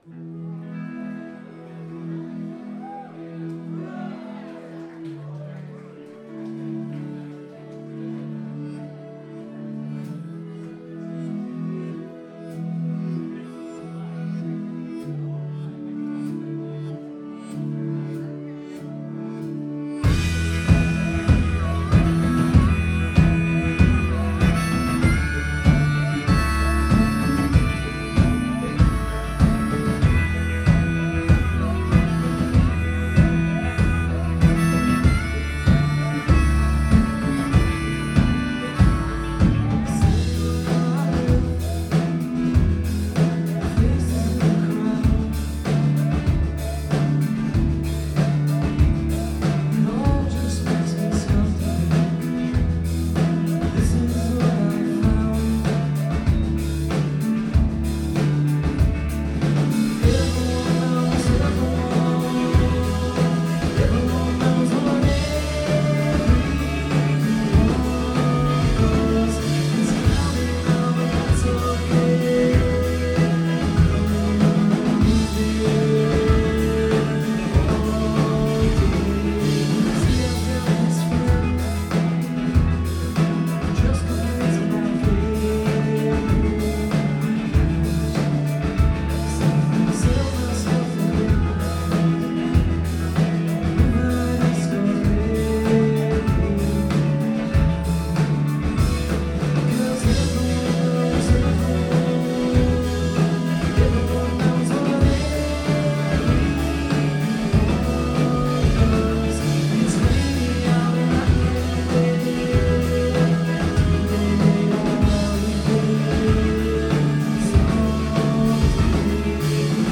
They are, in short, overwhelming live.